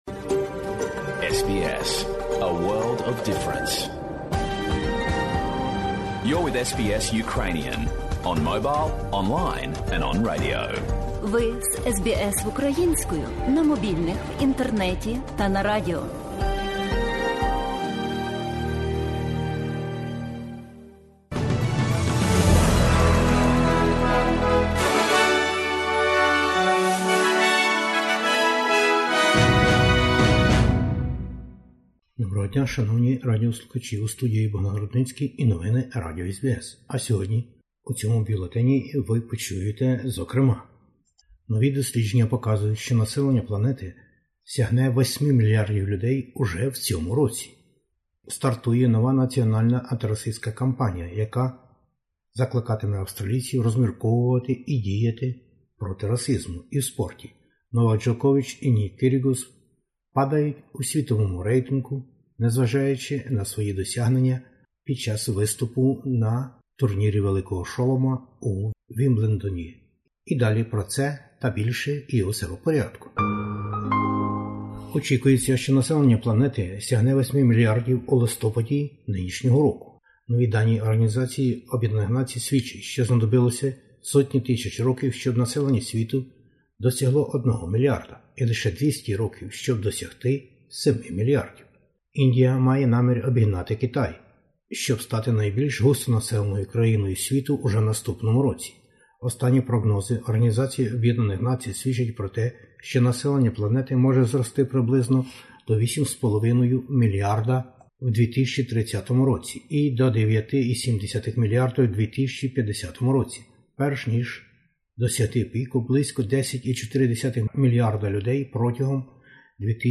Бюлетень SBS новин українською мовою. 8 мільярдів населення буде уже у листопаді поточного року.